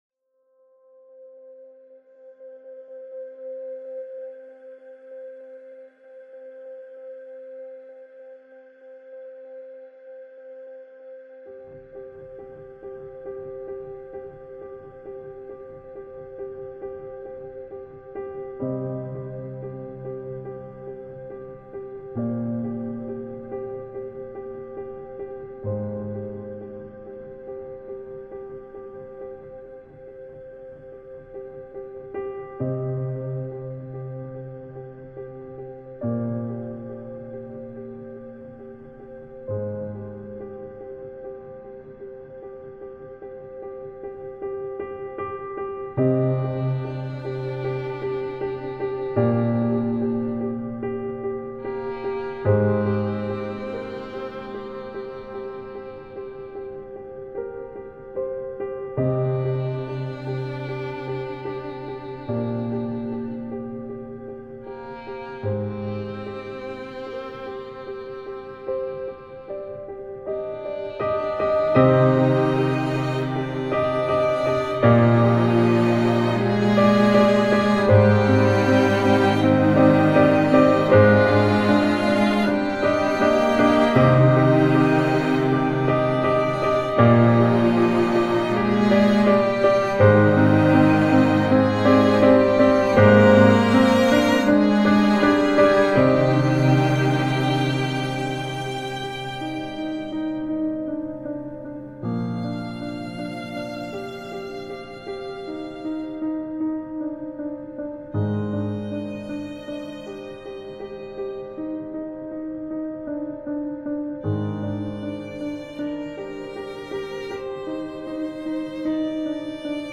موزیک بی کلام غمگین